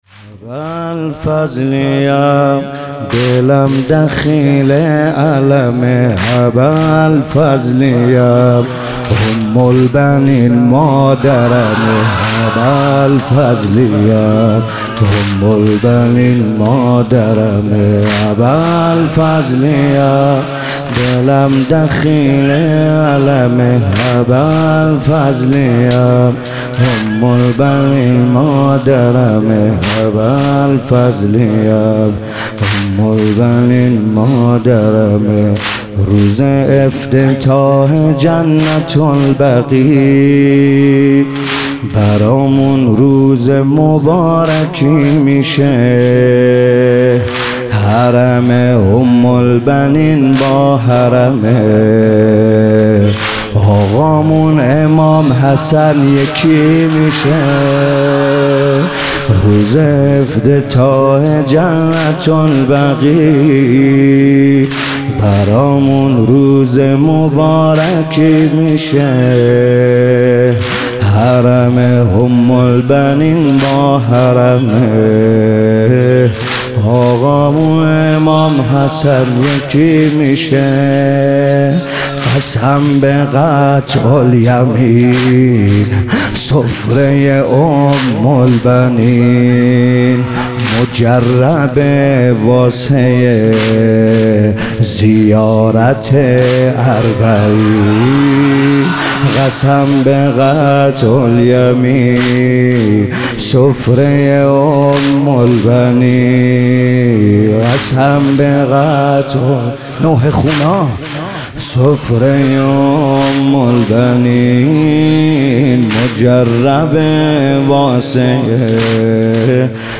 در این گزارش قطعه‌ها‌ی صوتی مداحی در مراسم وفات حضرت ام‌البنین(س) و پاسداشت شهدای مقاومت را می‌شنوید.
قطعه‌ها‌ی صوتی مداحی این مراسم را می‌شنوید.